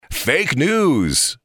Tags: Radio Show Sounds